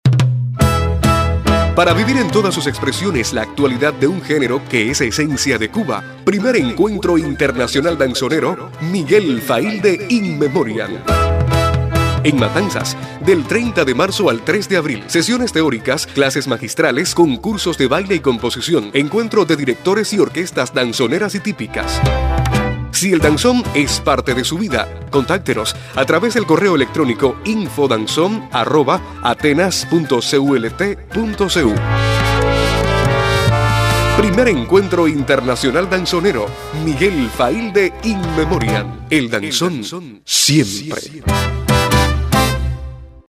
SPOT_RADIO.mp3